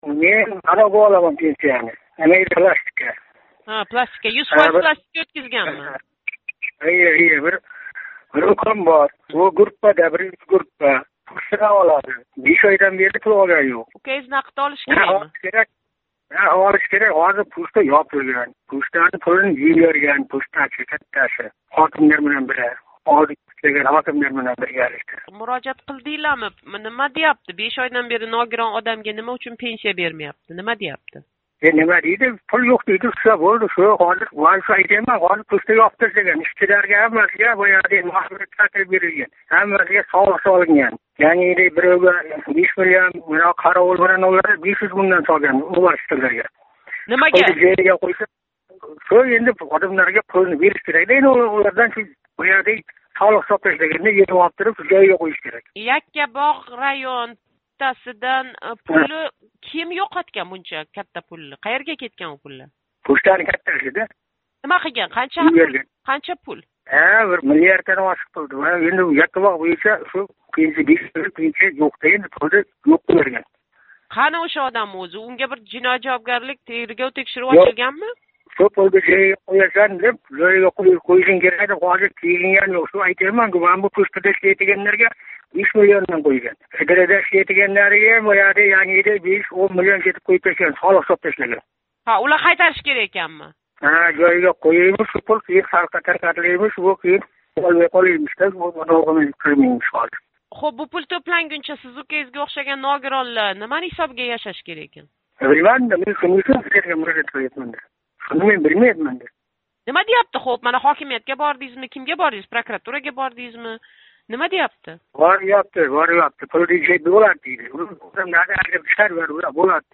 Беш ойдан бери нафақасиз қолган ногироннинг акаси билан суҳбат